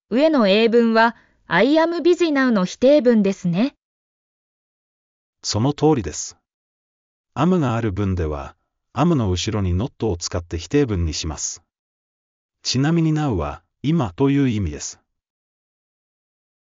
上の会話音声：©音読さん